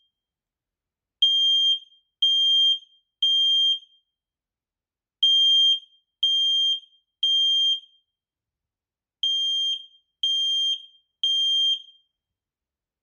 • Lautstärke im Test: 103,2 dBA
x-sense-xs01-wr-funkrauchmelder-alarm.mp3